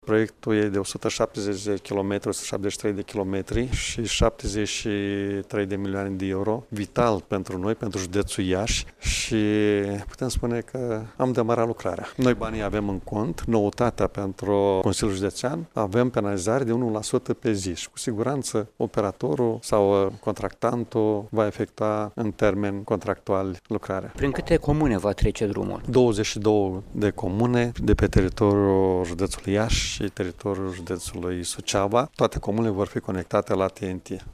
Preşedintele Consiliului Judeţean Iaşi, Maricel Popa, a precizat că drumul va străbate 22 de comune şi va deservi peste 400 de mii de locuitori din cele două judeţe.
Proiectantul are la dispoziţie 6 luni de zile pentru încheierea documentaţiei, astfel încât din toamnă, să se poată trece la lucrările de construcţie, a mai spus Maricel Popa: